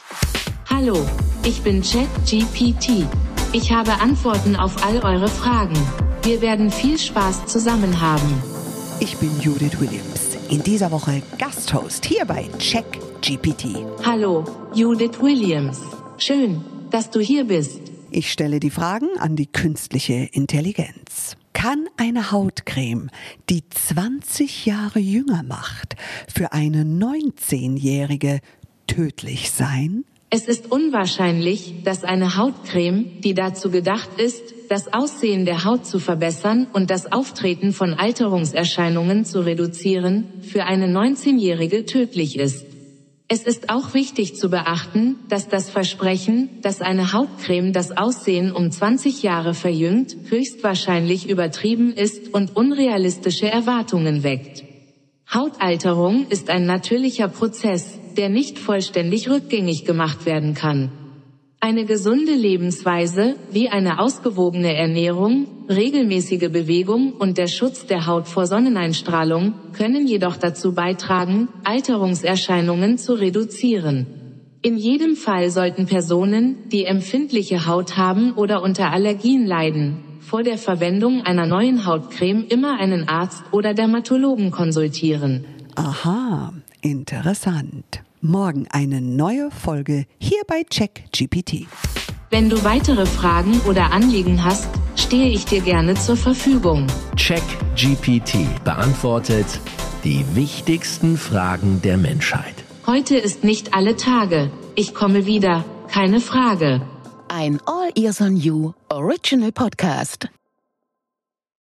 Judith Williams & KI
Herzlich Willkommen an unseren Gast-Host Judith Williams!